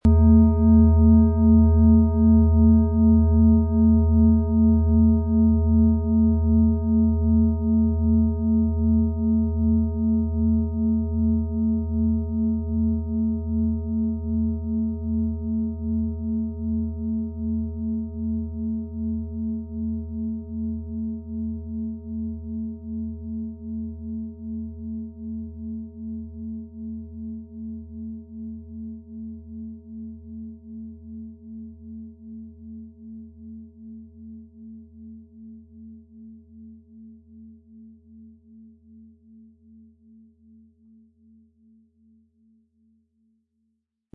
Planetenton 1
Die Planetenklangschale Venus wurde in einem uralten Meisterbetrieb, in aufwendiger Handarbeit getrieben.
Den passenden Schlägel erhalten Sie kostenlos mitgeliefert, er lässt die Klangschale harmonisch und wohltuend ertönen.